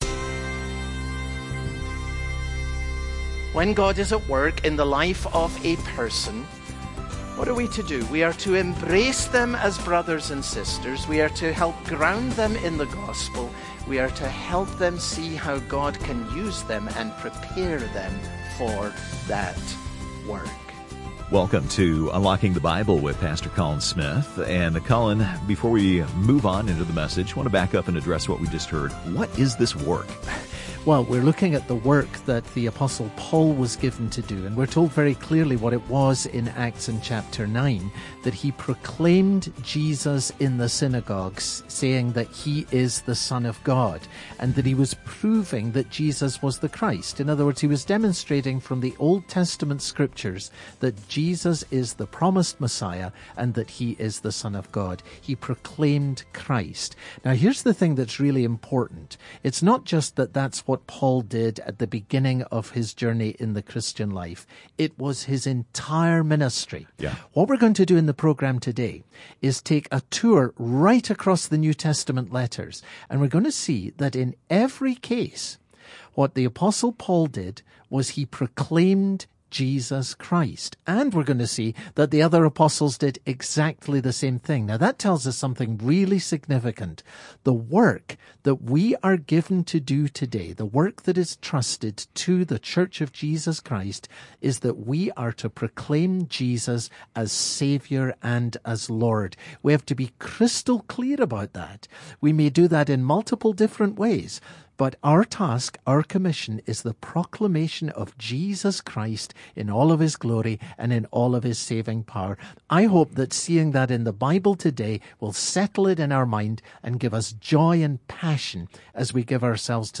Part 2 Acts Broadcast Details Date Mar 11